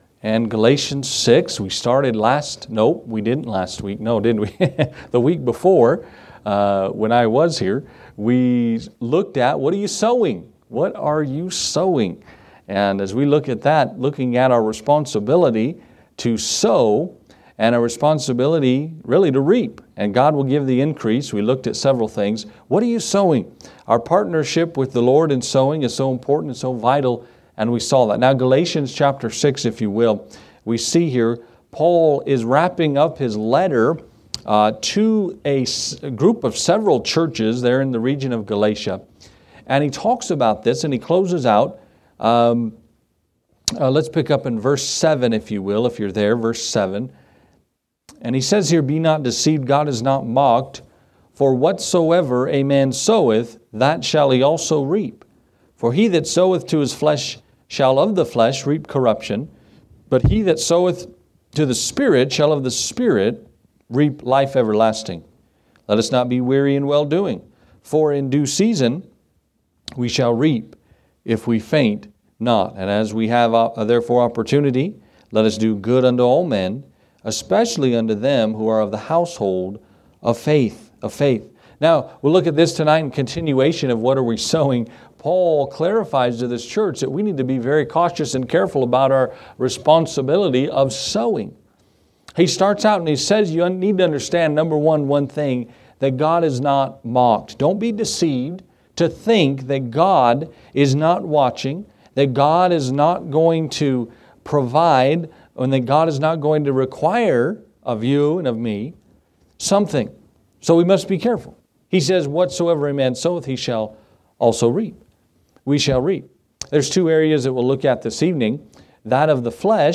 Gospel Message